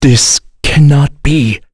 Kain-Vox_Dead.wav